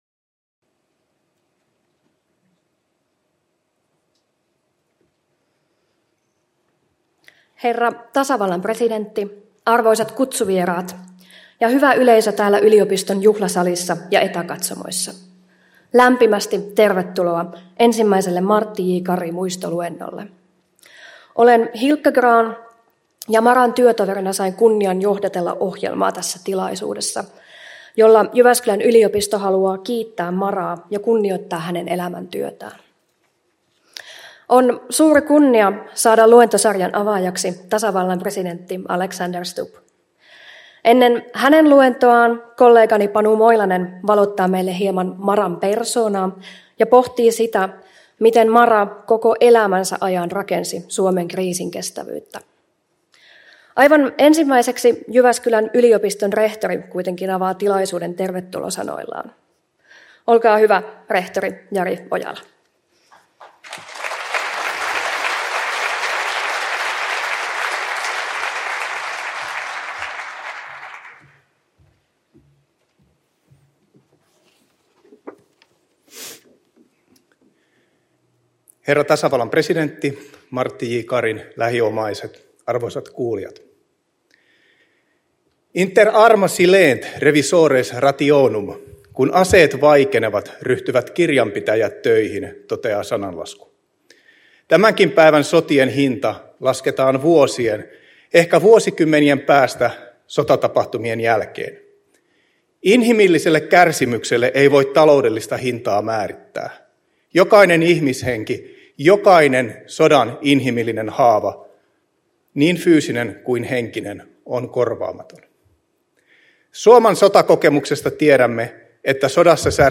Martti J. Kari -muistoluento 2024 — Moniviestin